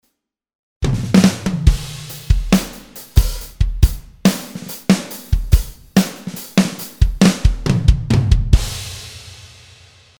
Drums - "out of the Box" - Wer hört sich realistisch an ?
Alle Beispiele sind unbearbeitet "out of the Box".